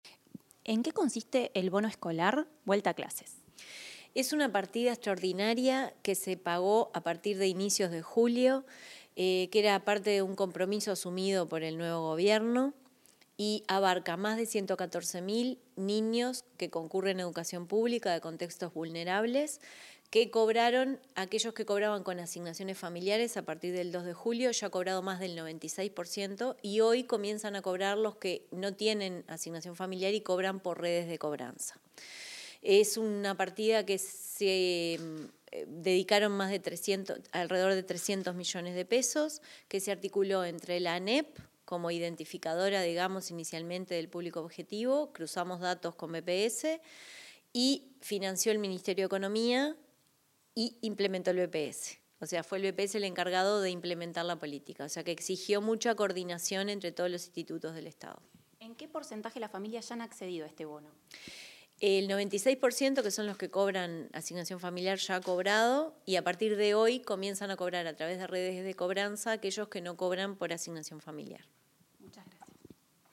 Declaraciones de la presidenta de BPS, Jimena Pardo